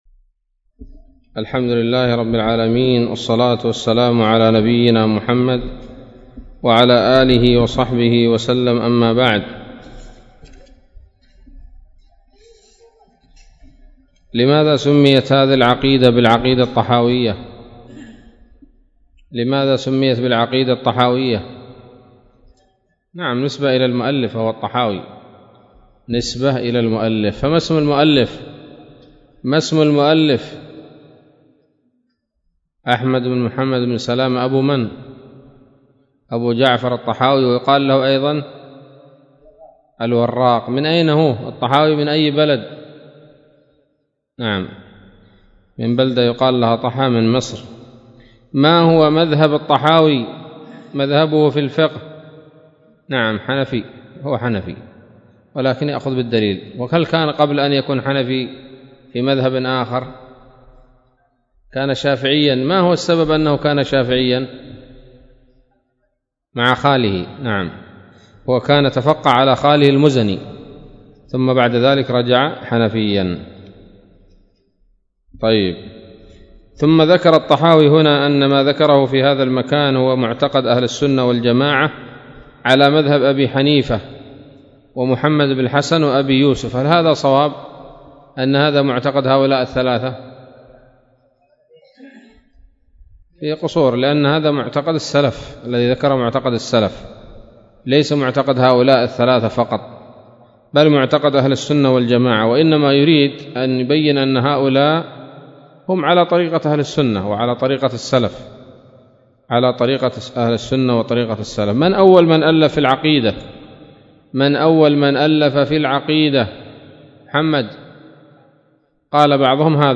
الدرس الثاني من شرح العقيدة الطحاوية